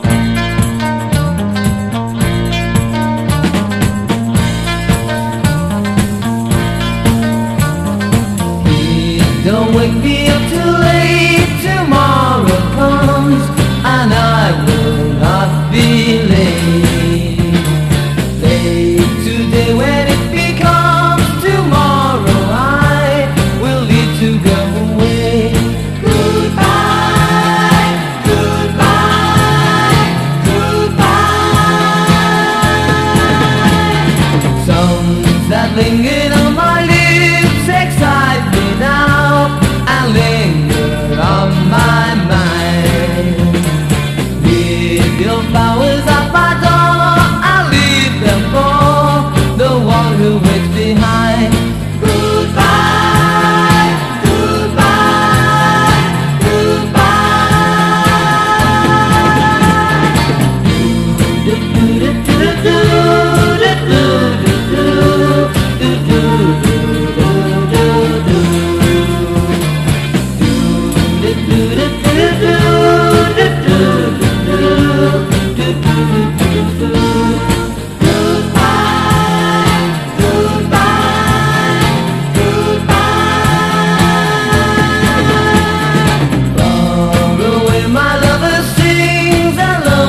ROCK / 90''S～ / 10'S (US)
ベースをギターに持ち替え、全編でヴォーカルを披露しています！